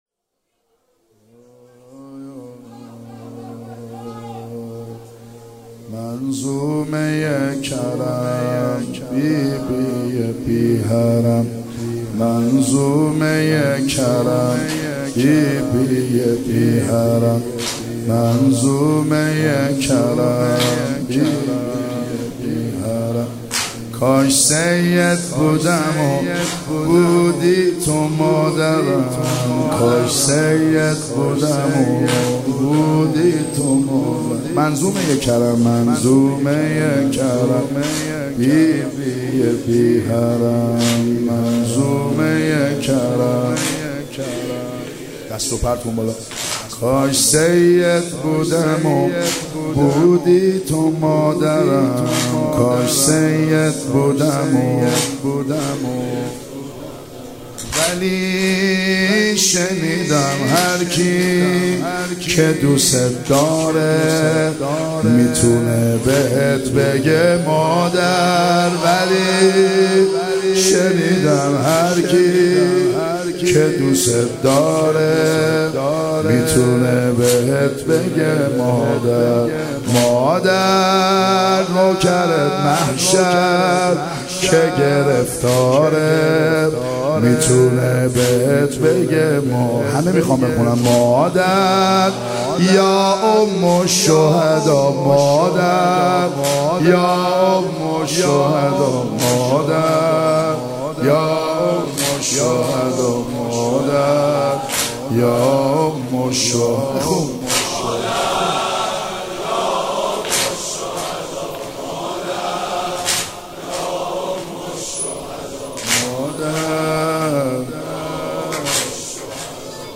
شب چهارم فاطمیه دوم صوتی -واحد - کاش سید بودمو تو بودی مادرم - محمد حسین حدادیان
شب چهارم فاطمیه دوم صوتی